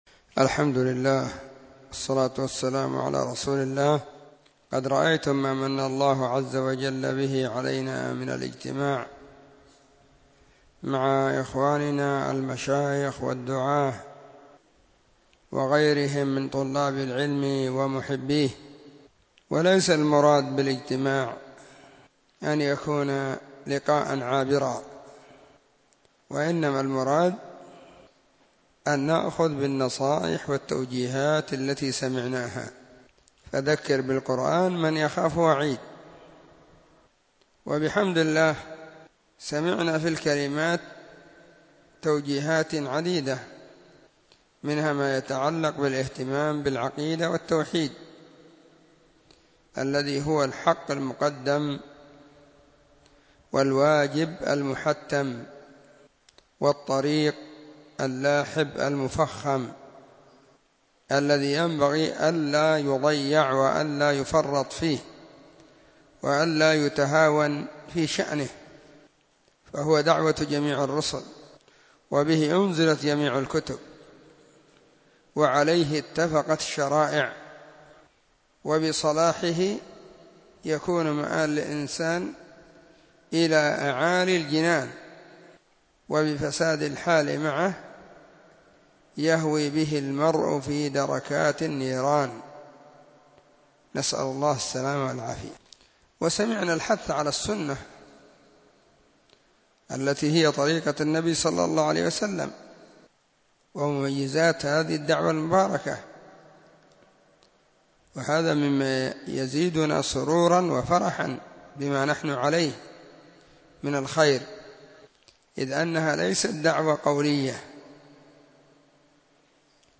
📢 مسجد – الصحابة – بالغيضة – المهرة، اليمن حرسها الله.